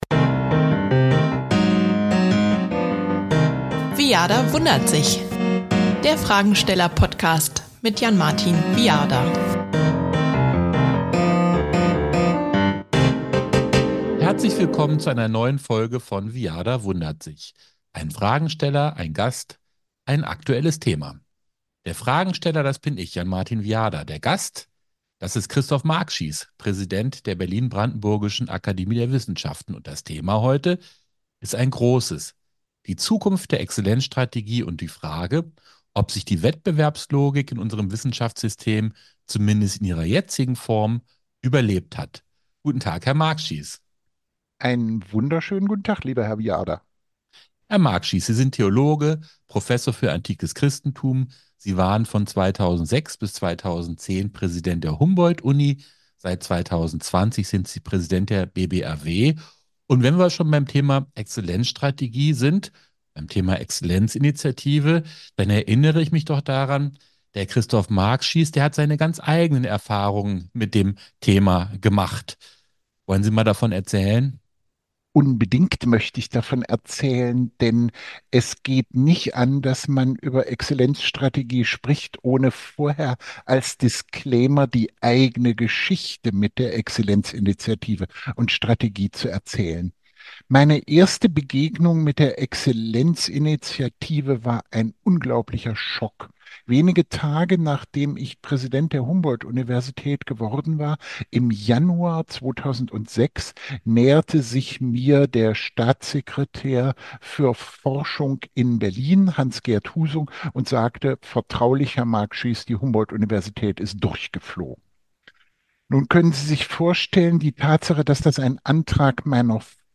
Beschreibung vor 1 Jahr Welche Rolle spielt der Wettbewerb in der Wissenschaft? Wird er in der Exzellenzstrategie angemessen umgesetzt? Und wie sollte die "ExStra" weitergehen? Ein Gespräch mit BBAW-Präsident Christoph Markschies über bittere persönliche Erfahrungen, die Eigenlogik der Wissenschaft – und Bundesligavergleiche.